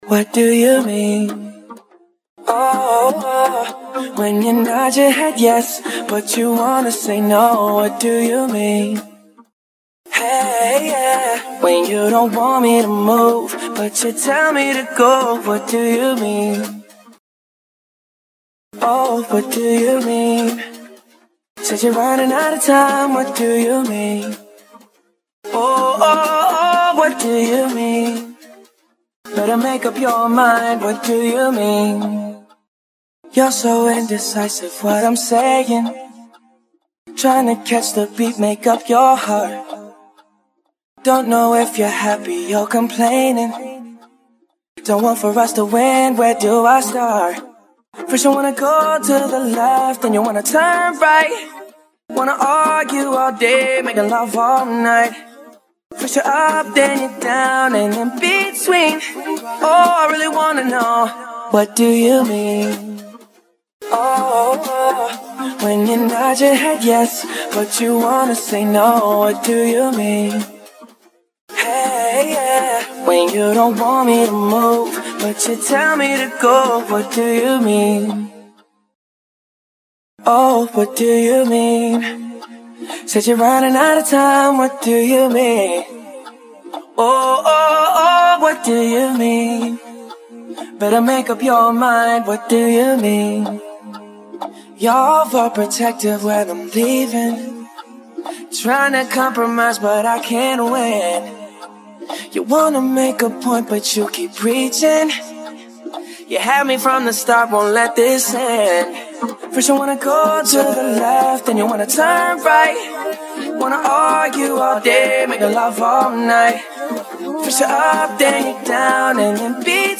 Almost Studio Acapella